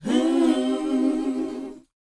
WHOA A#B.wav